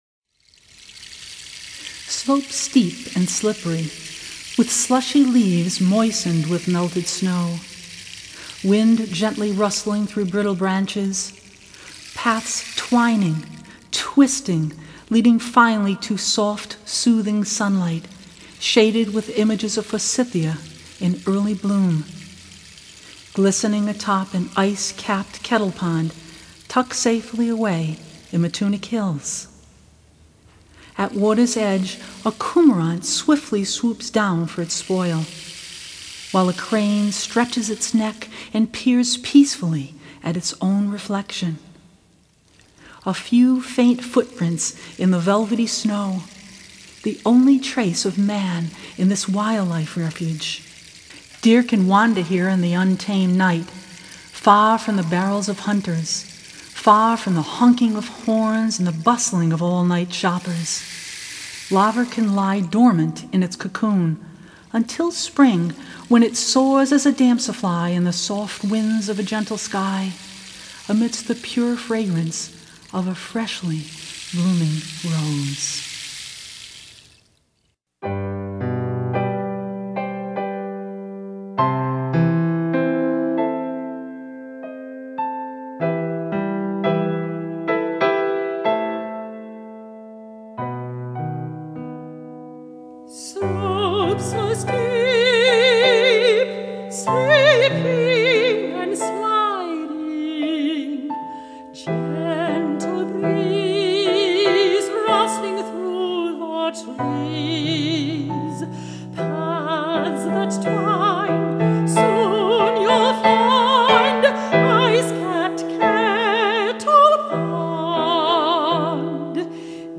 Chamber Ensemble